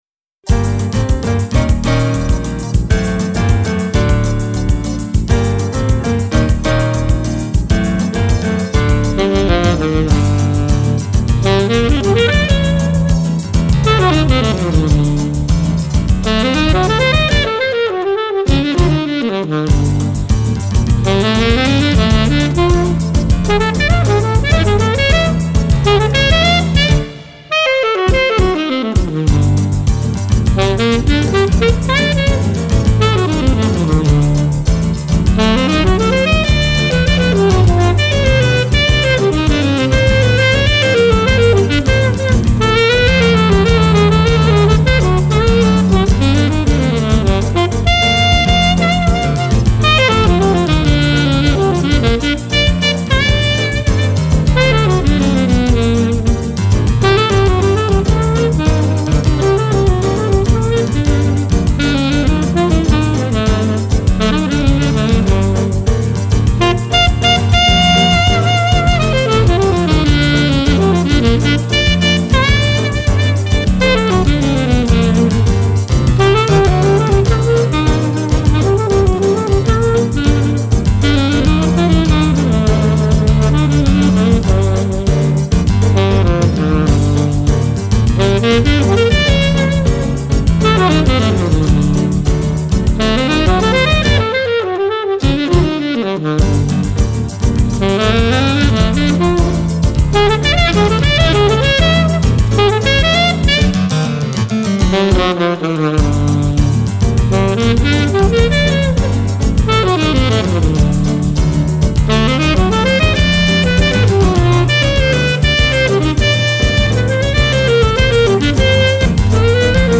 04:20:00   Choro(Chorinho)